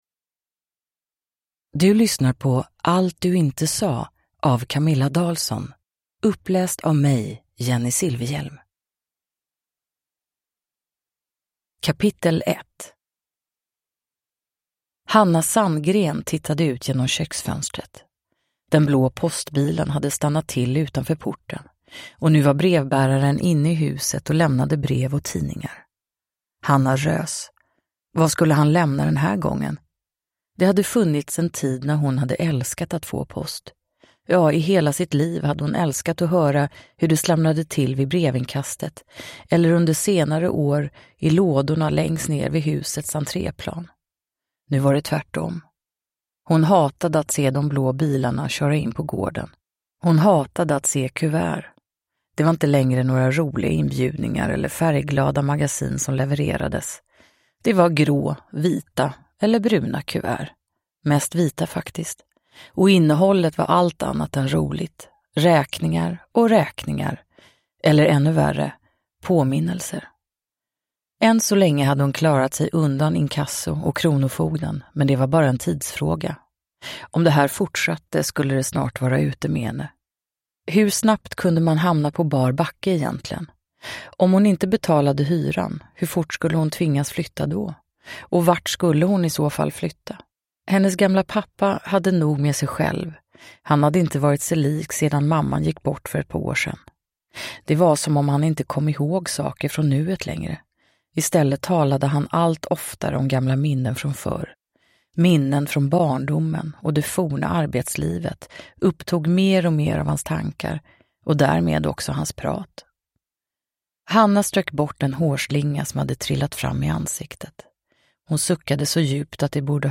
Uppläsare: Jennie Silfverhjelm
Ljudbok